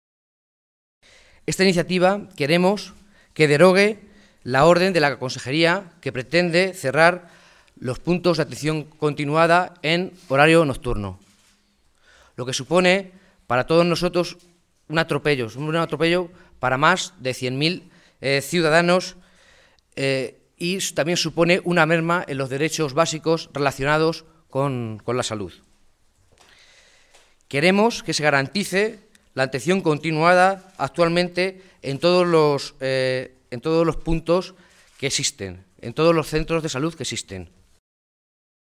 Rueda de prensa de los distintos alcaldes socialistas afectados por el cierre de los PAC en la Región
Cortes de audio de la rueda de prensa
Audio alcalde de Tembleque-2